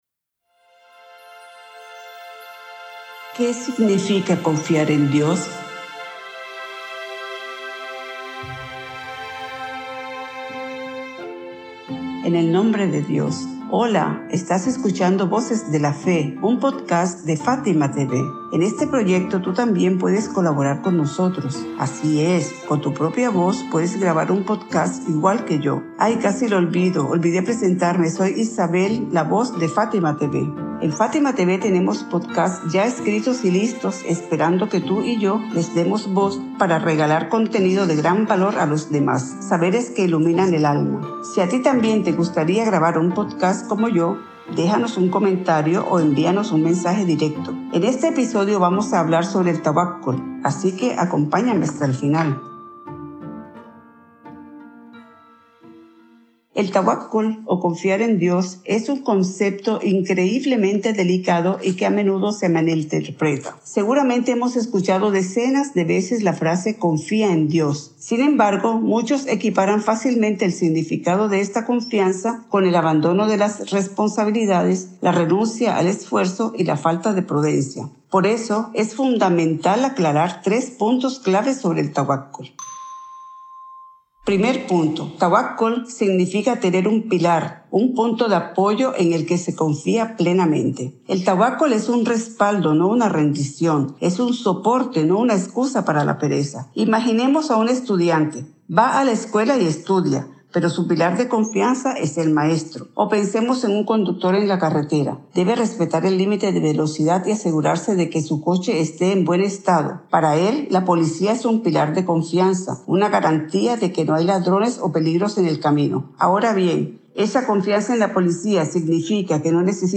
En este episodio de Voces de la Fe hablamos sobre el verdadero significado de confiar en Dios (tawakkul). Explicamos qué es la confianza sincera en Él, cómo evitar confundirla con pasividad y por qué el tawakkul trae fortaleza, serenidad y esperanza al corazón del creyente. 🎙 Locutora: